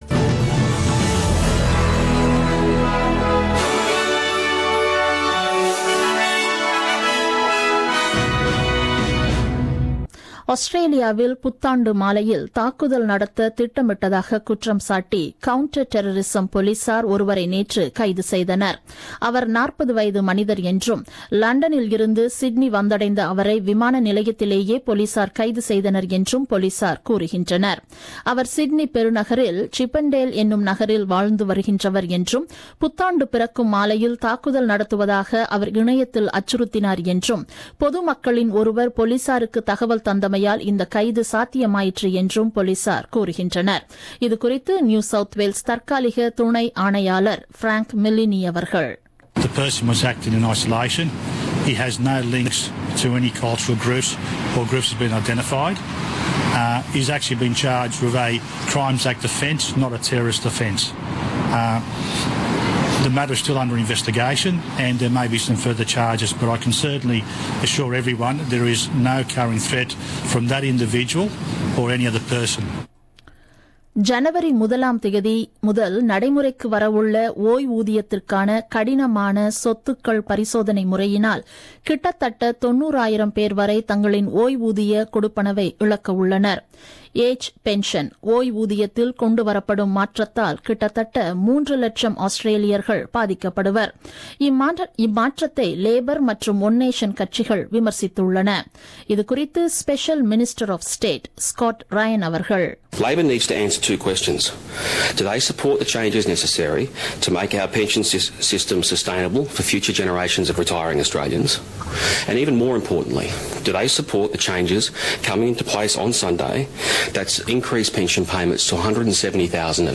The news bulletin broadcasted on 30 Dec 2016 at 8pm.